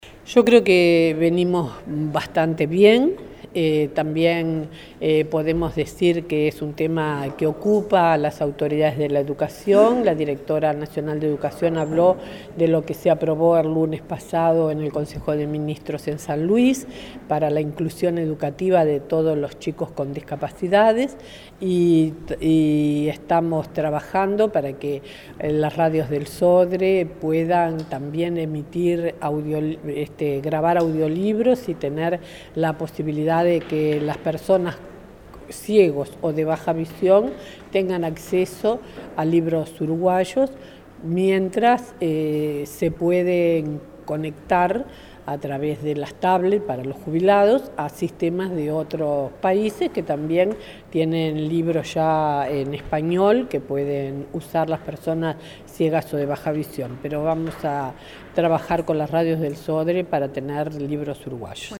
“Estamos trabajando para que las radios del Sodre puedan grabar audiolibros para que personas ciegas o con baja visión tengan acceso a libros uruguayos”, subrayó la ministra de Educación, María Julia Muñoz, este miércoles durante un seminario sobre el derecho a la accesibilidad y el Tratado de Marrakech.